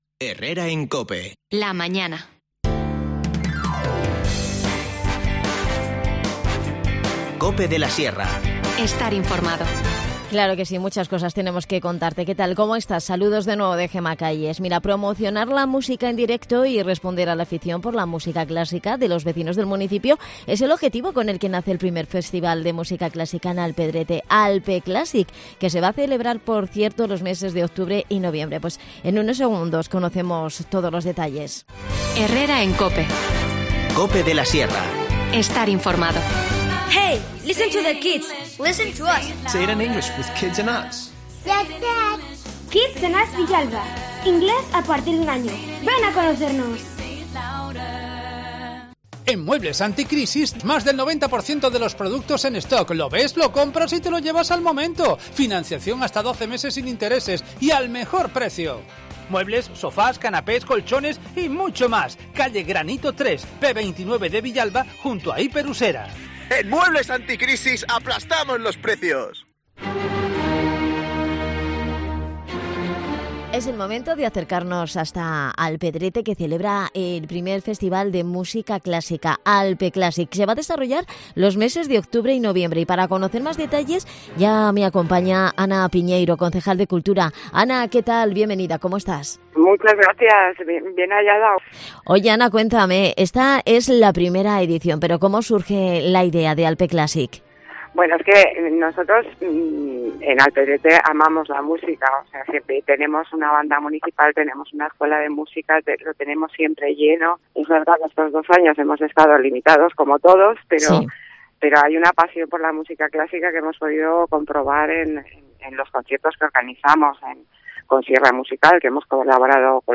Nos cuenta todos los detalles Ana Piñeiro, concejal de Cultura.